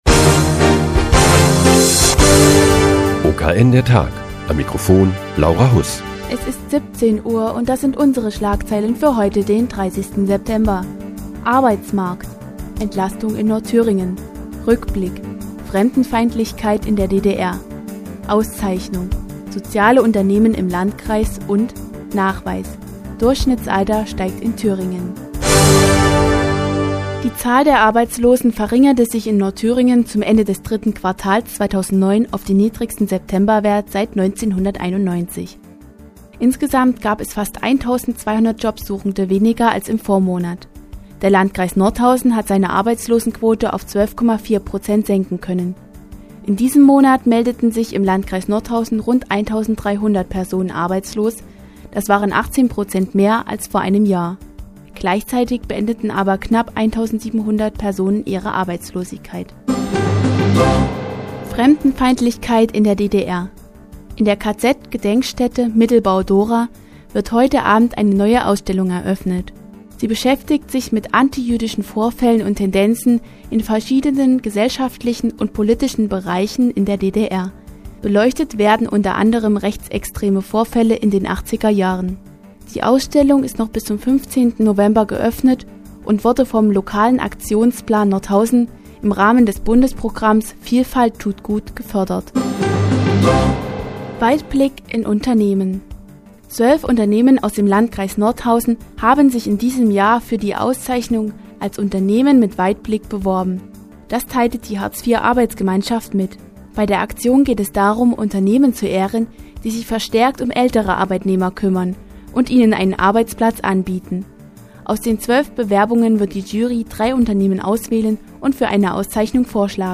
Die tägliche Nachrichtensendung des OKN ist nun auch in der nnz zu hören. Heute geht es um eine Auszeichnung für Unternehmen und das Thüringer Durchschnittsalter.